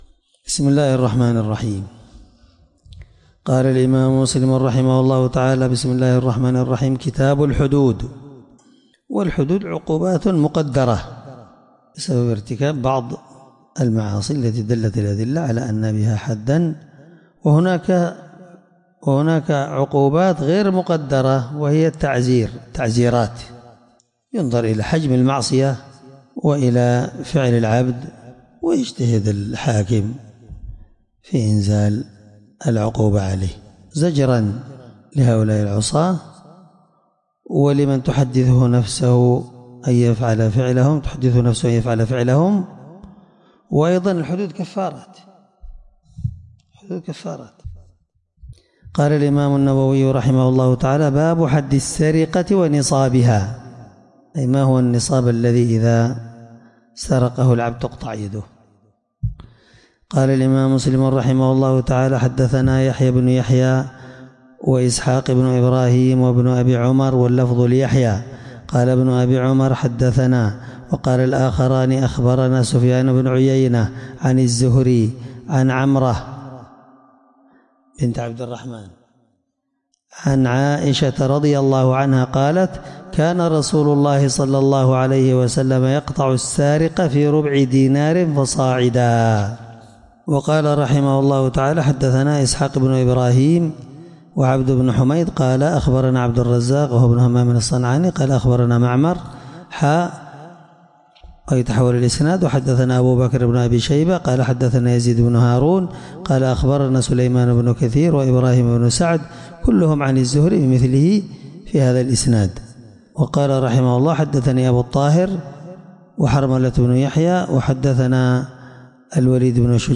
الدرس1من شرح كتاب الحدود حديث رقم(1684-1687) من صحيح مسلم